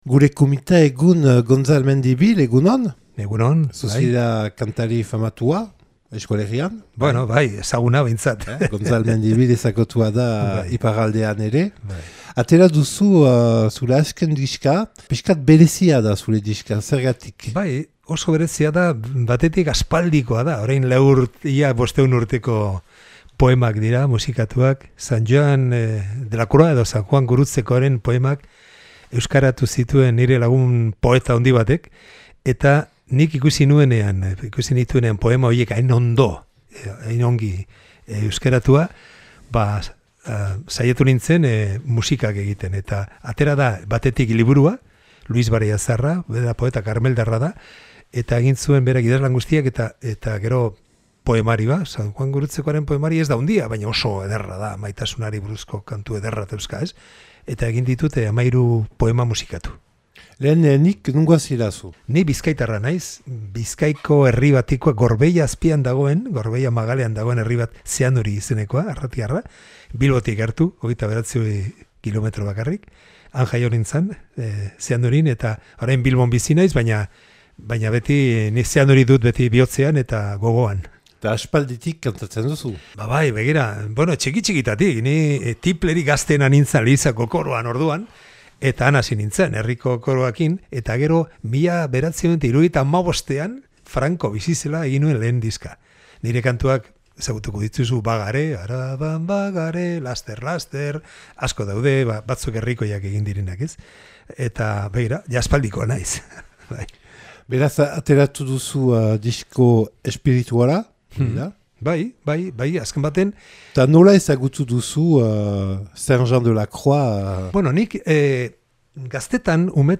Elkarrizketak eta erreportaiak